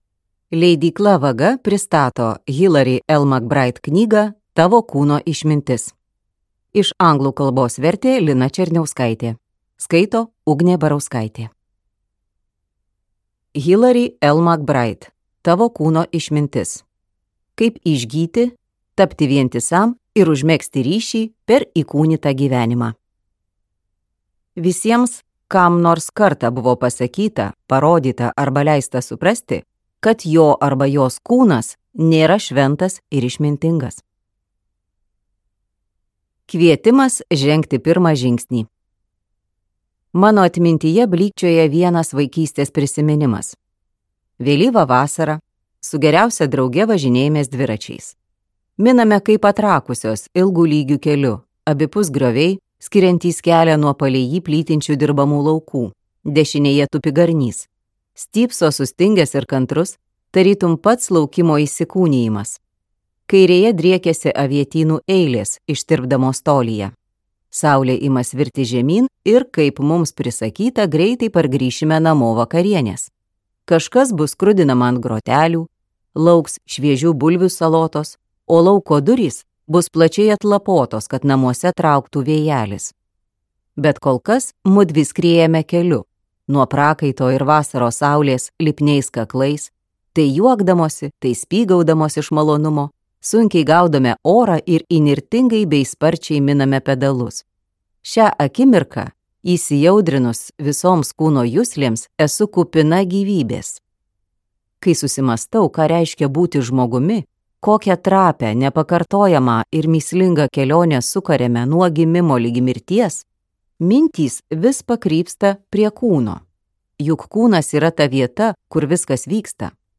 Tavo kūno išmintis | Audioknygos | baltos lankos